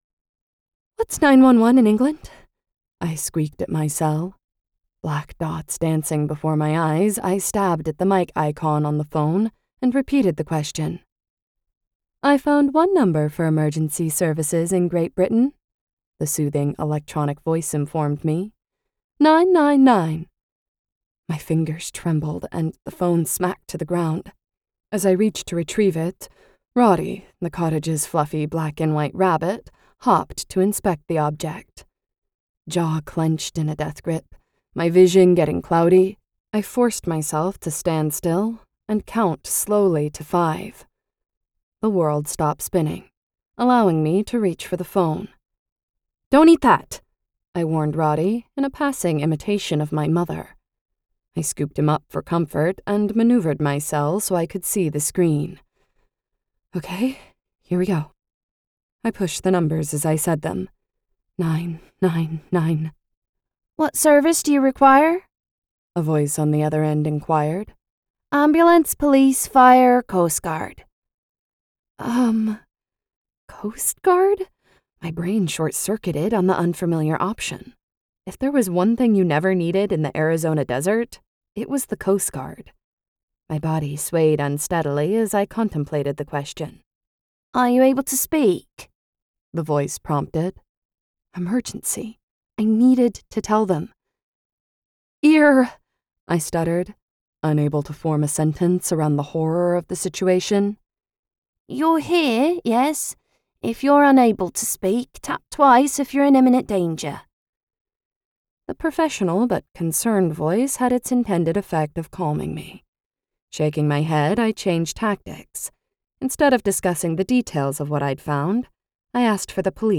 Death Takes a Bath - A Cotswold Crimes Mystery, Book One - Vibrance Press Audiobooks - Vibrance Press Audiobooks